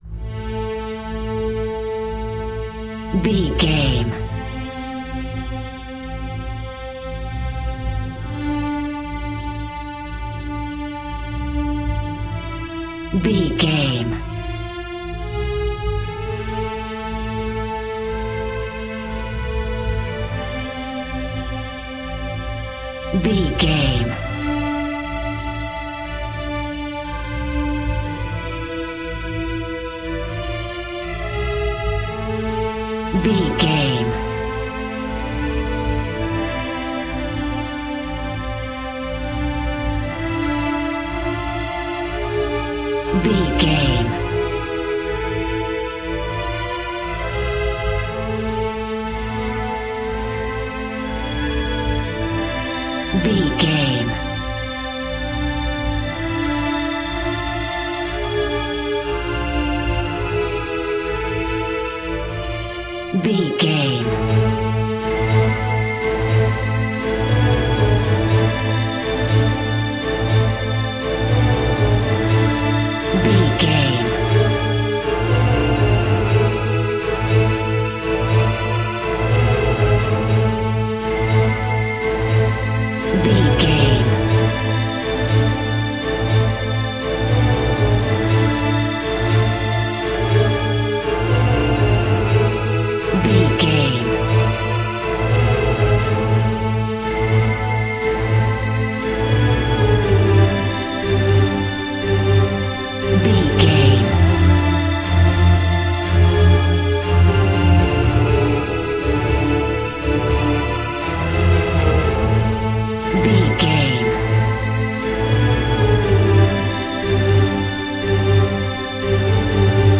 In-crescendo
Thriller
Aeolian/Minor
tension
ominous
dark
suspense
eerie
strings
synth
ambience
pads